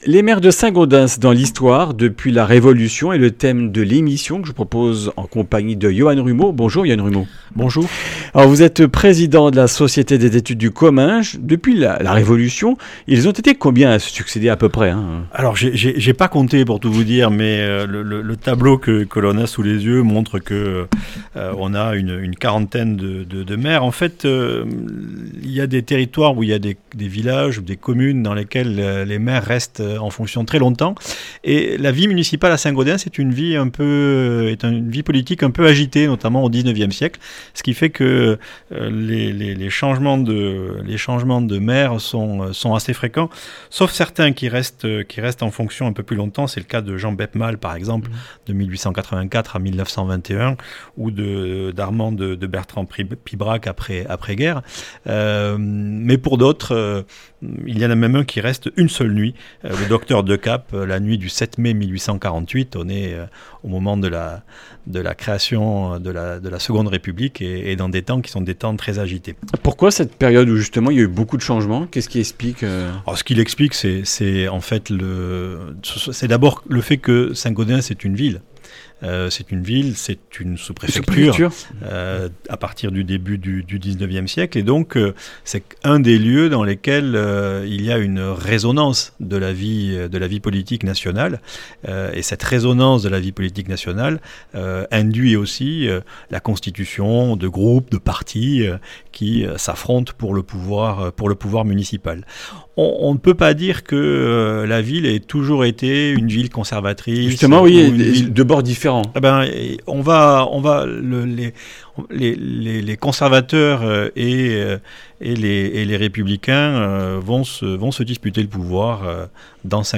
Comminges Interviews du 24 mars
Journaliste